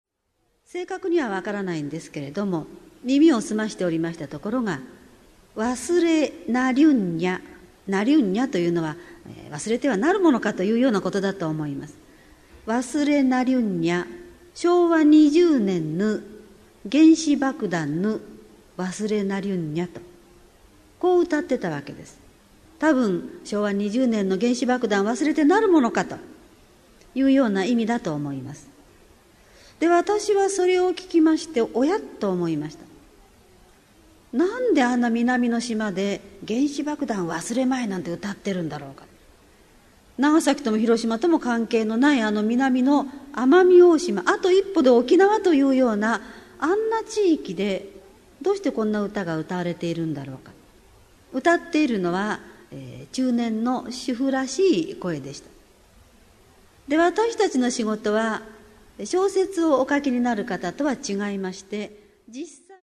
名だたる文筆家が登場する、文藝春秋の文化講演会。
（1989年9月6日 滝川市文化センター 菊池寛生誕百周年記念講演会より）